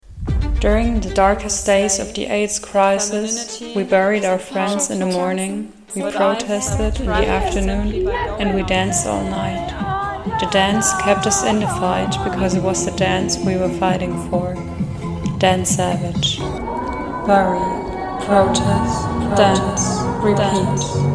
Soundcollagierend gehen wir auf den ersten CSD in Hildesheim, wir lesen Statistiken zu transfeindlicher Gewalt, wie immer weinend und dann chanten wir ein Gedicht von ALOK, um uns zu reminden, wie magical und powerful wir trans* people sind.